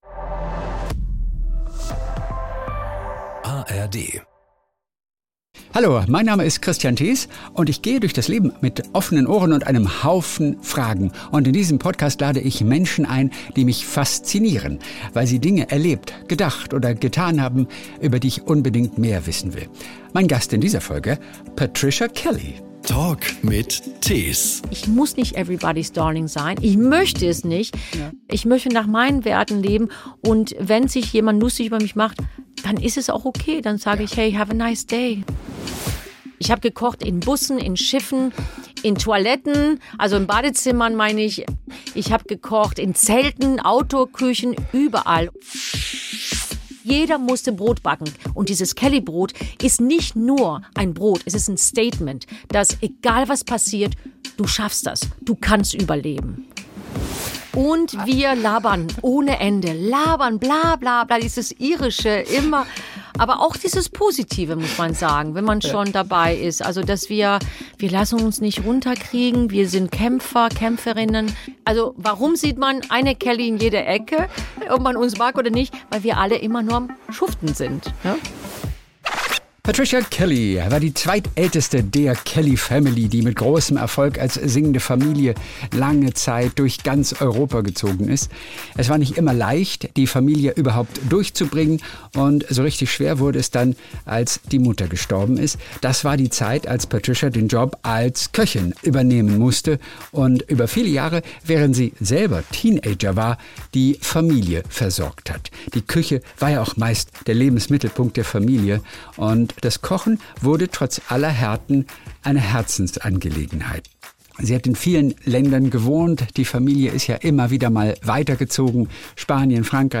Es ist ein Gespräch voller Herz, Humor und Offenheit. Patricia Kelly spricht dabei auch über schwierige Kapitel ihres Lebens.